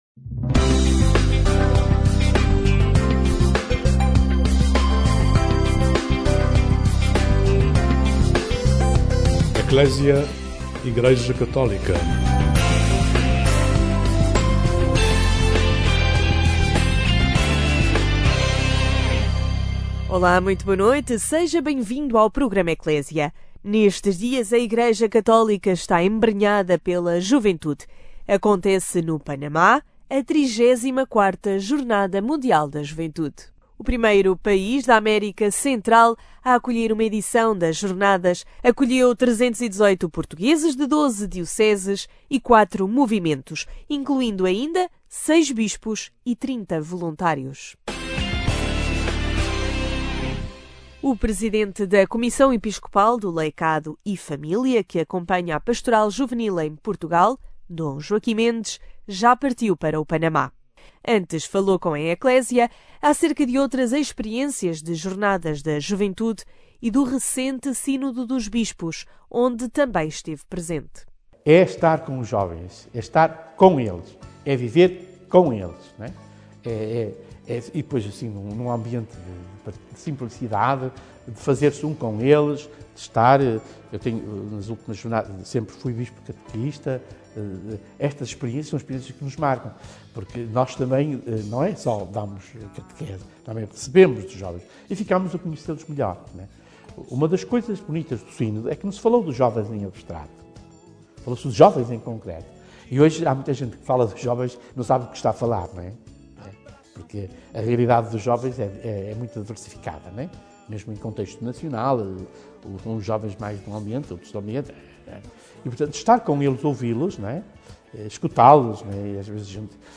No dia em que o Papa Francisco chegará ao Panamá para a 34ª Jornada Mundial da Juventude o programa Ecclesia traz o testemunho do bispo auxiliar de Lisboa, D. Joaquim Mendes, também ele naquele país da América Central para este encontro da juventude.